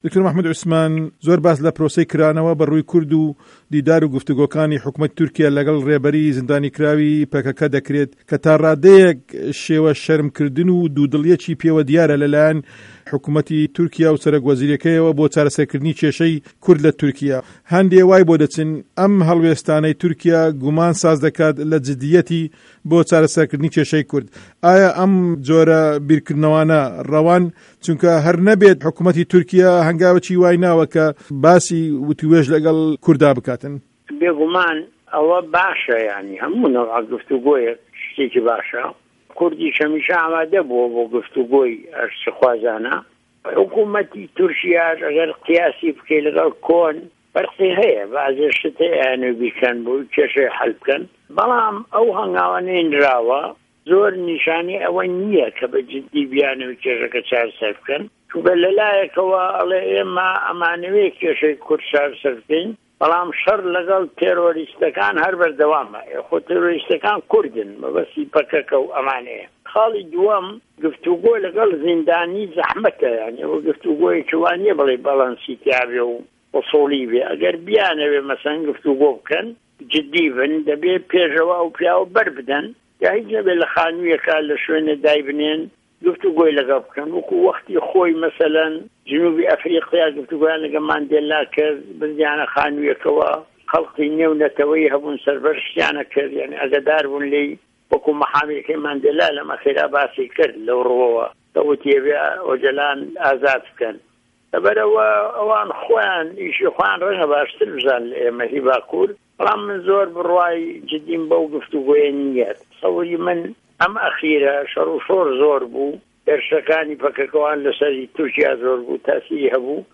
وتووێژی دکتۆر مه‌حمود عوسمان